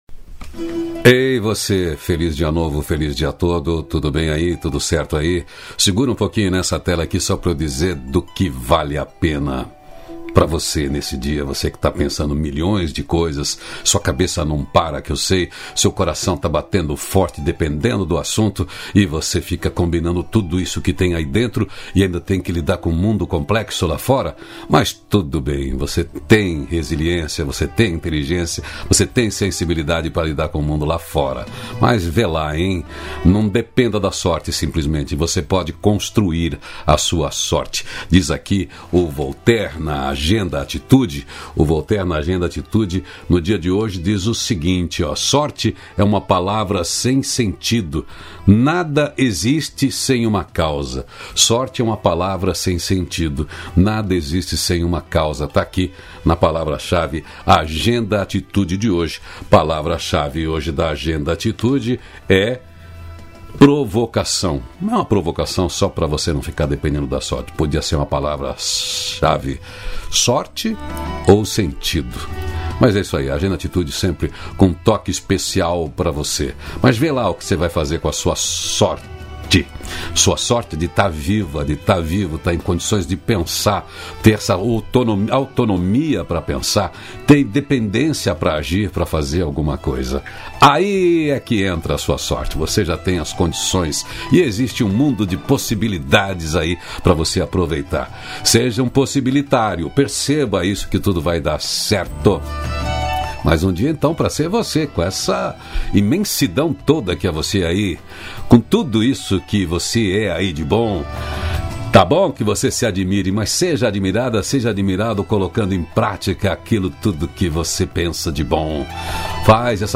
O diálogo nutritivo de hoje